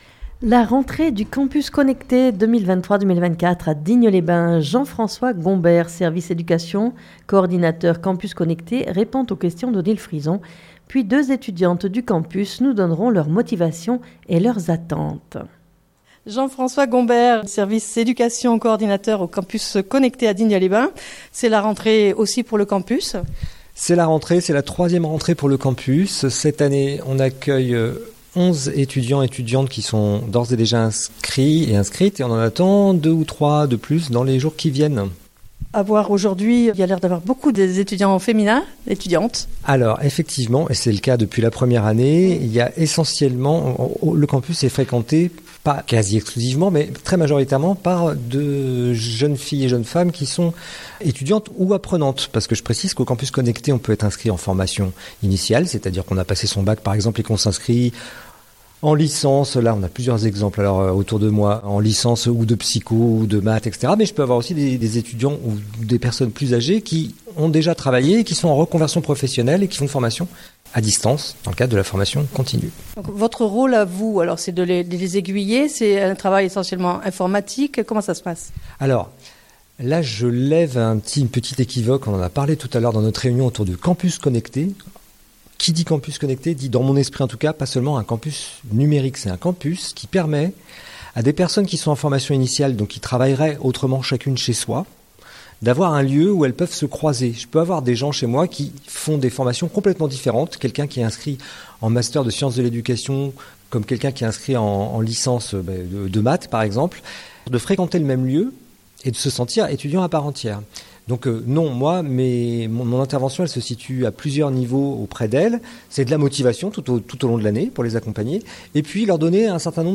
puis 2 étudiantes du Campus nous donneront leurs motivations, et leurs attentes.